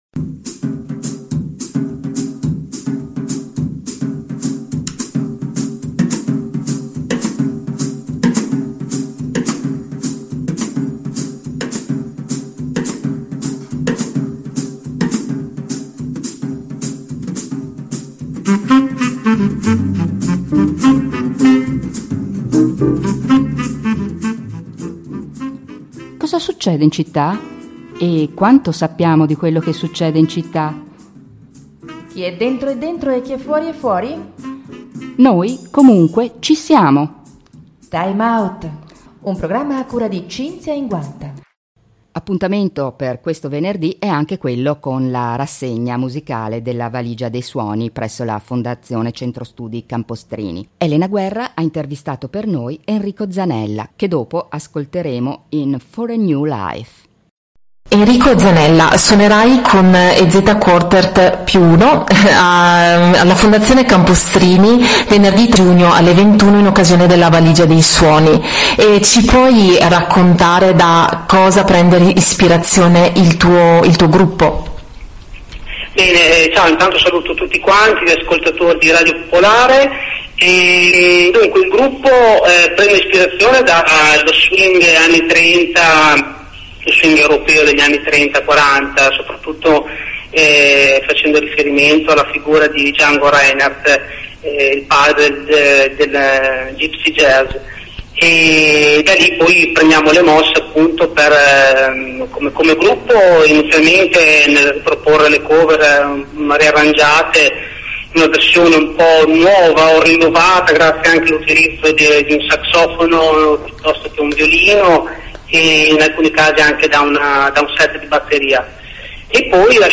Intervista
Intervista all'interno del programma Time Out di Radio Popolare Verona.